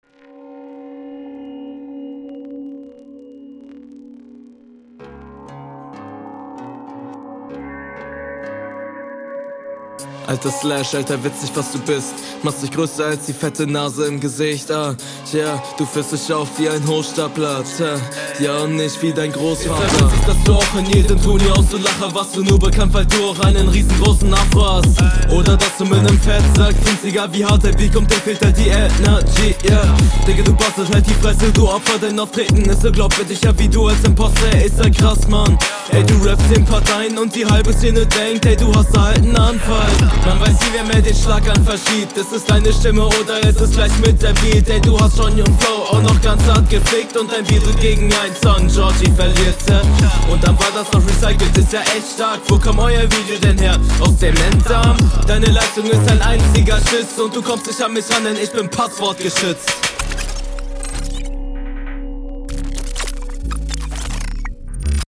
Gehst auf dem Beat leider bisschen unter aber dennoch stark